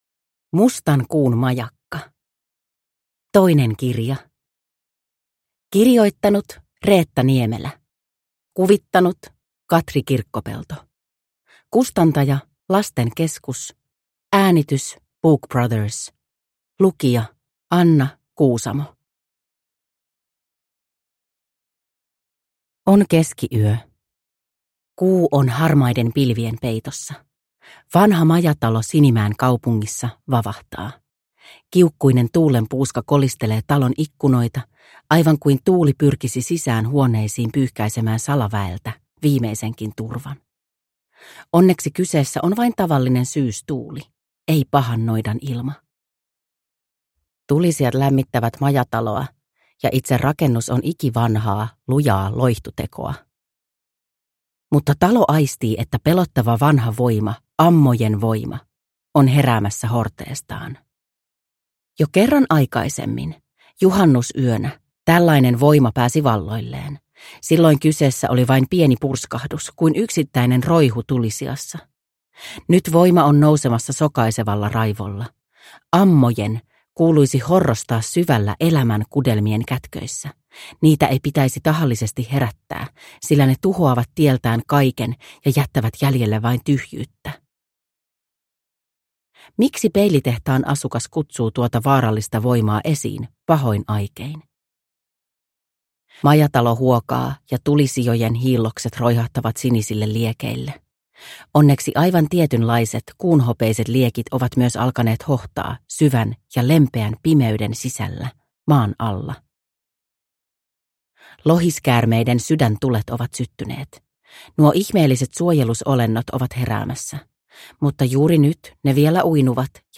Mustan Kuun majakka – Ljudbok – Laddas ner